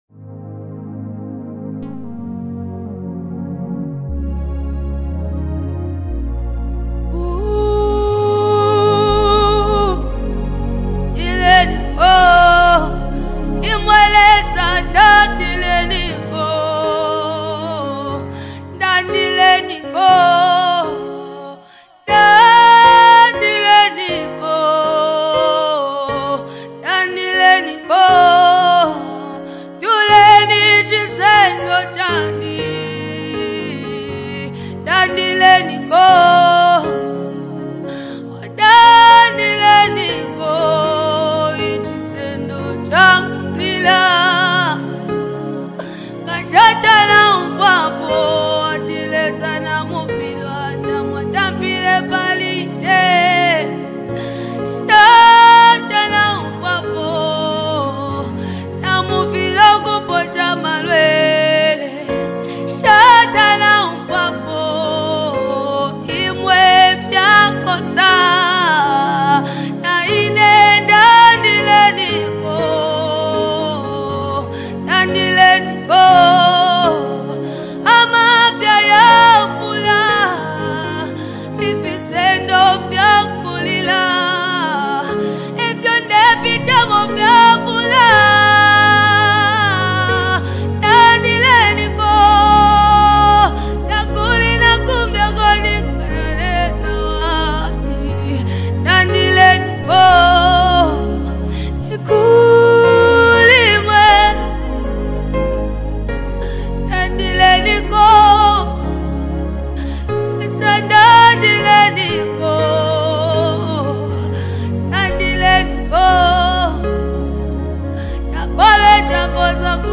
Zambian Worship Songs